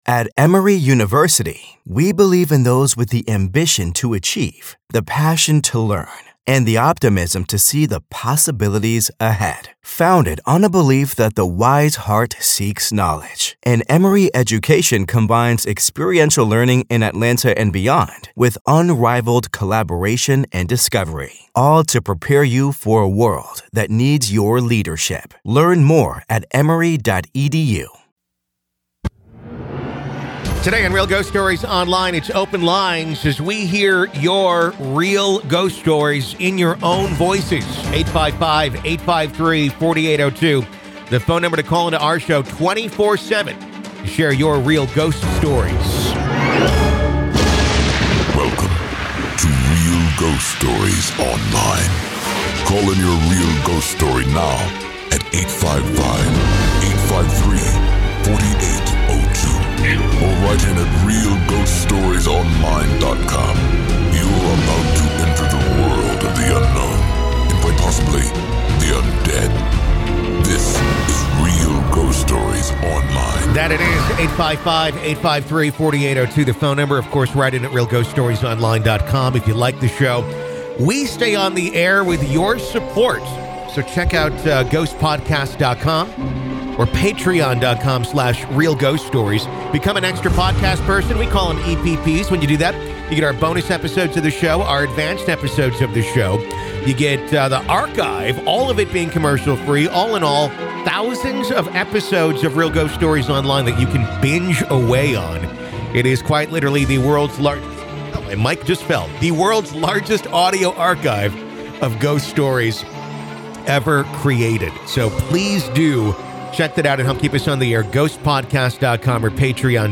Today we hear true ghost stories from real people.